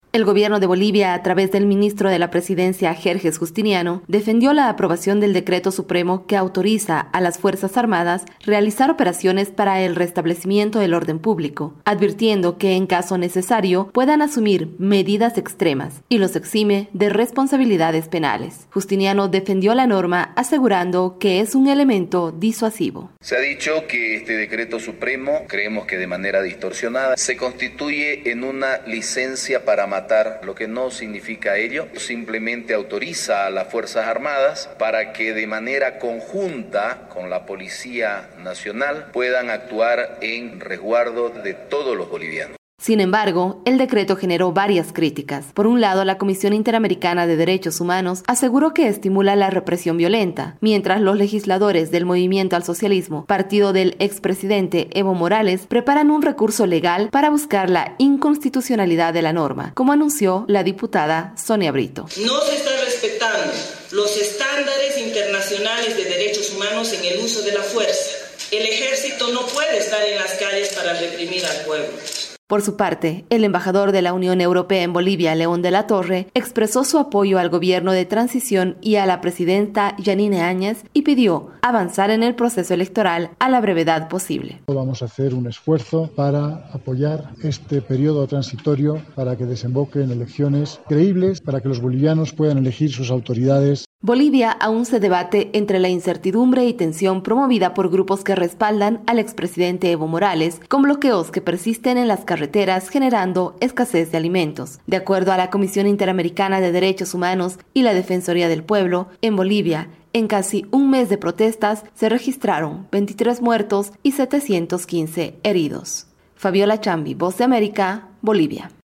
VOA: Informe de Bolivia